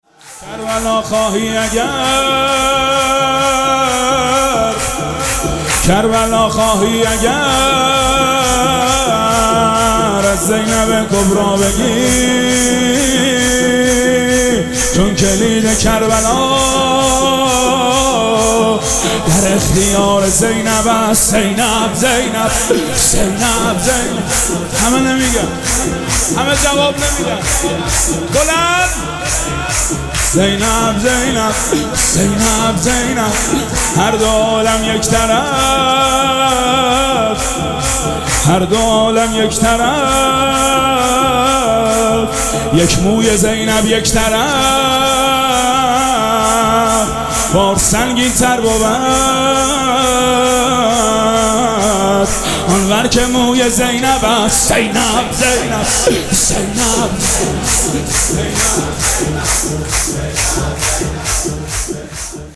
مراسم جشن ولادت حضرت زینب سلام‌الله‌علیها
سرود